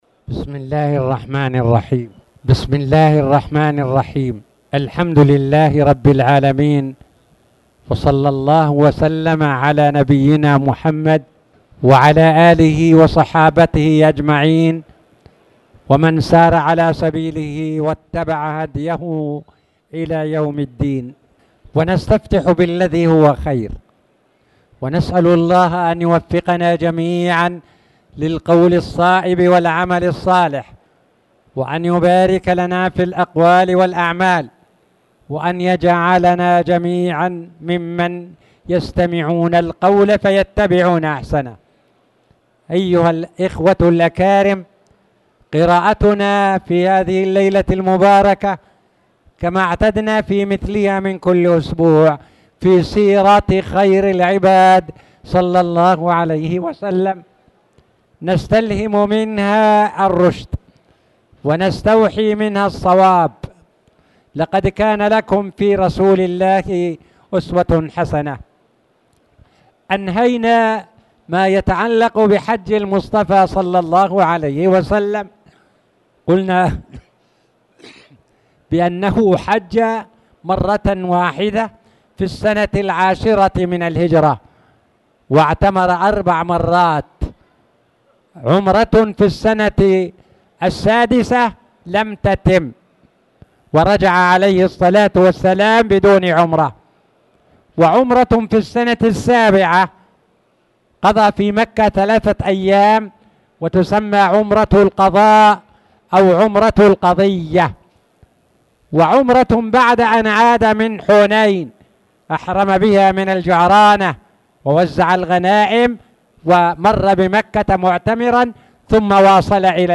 تاريخ النشر ١٦ ربيع الأول ١٤٣٨ هـ المكان: المسجد الحرام الشيخ